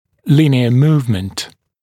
[‘lɪnɪə ‘muːvmənt][‘линиэ ‘му:вмэнт]линейное перемещение